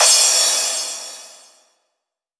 Crashes & Cymbals
MUB1 Crash 014.wav